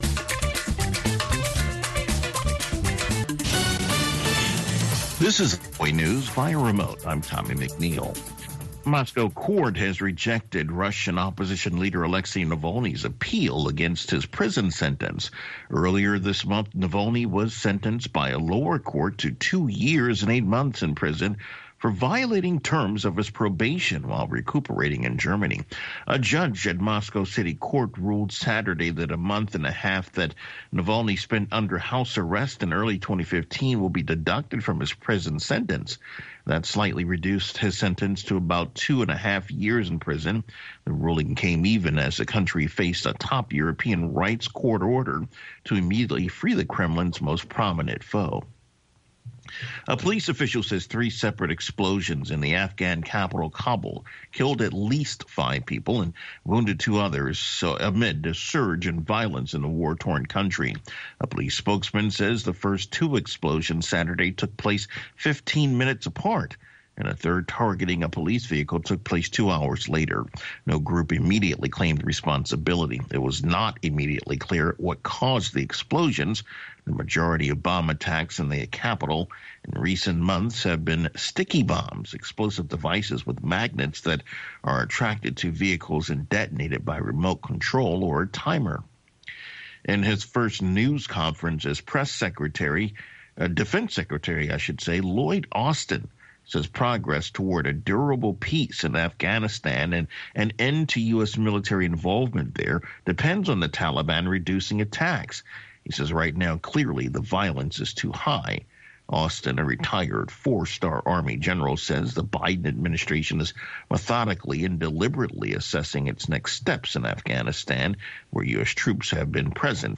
exclusive interviews